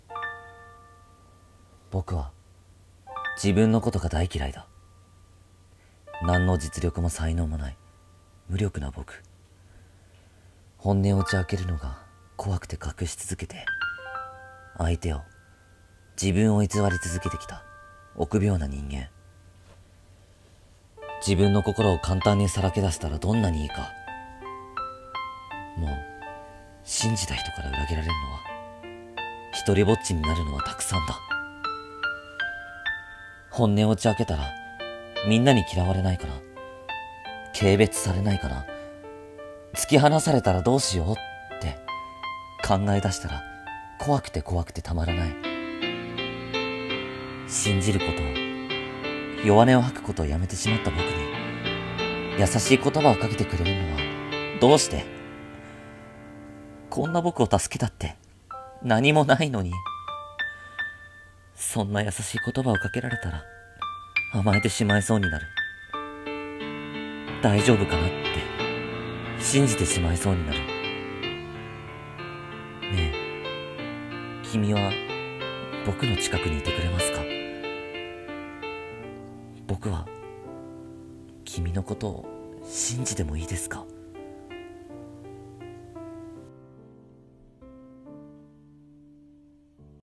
【一人声劇】信じてもいいですか？